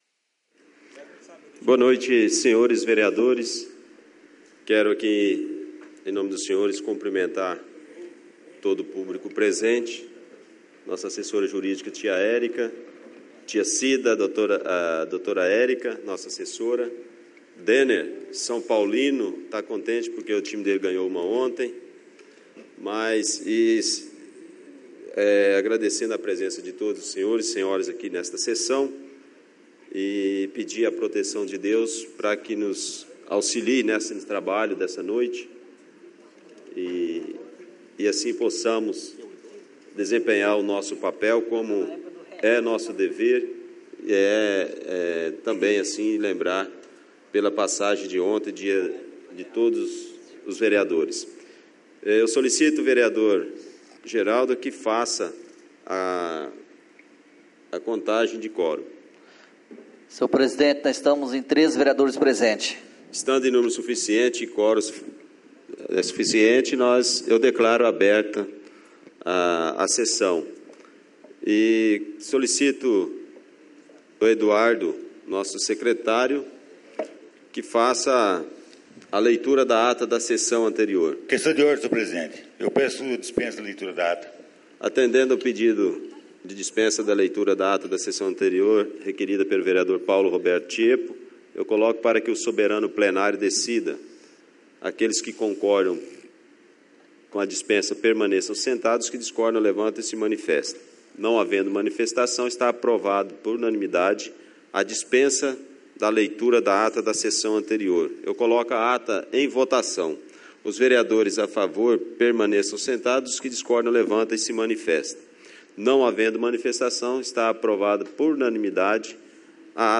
Áudio na integra da Sessão Ordinária realizada no dia 02/10/2017 as 20 horas no Plenário Henrique Simionatto.